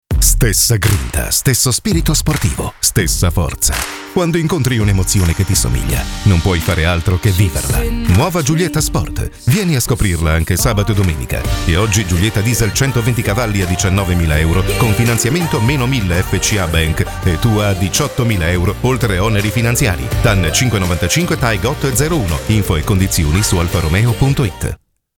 Automotivo
Posso descrever minha voz como uma voz masculina de "meia-idade", profunda, calorosa, comunicativa, para documentários ou onde o sentimento é necessário.
Barítono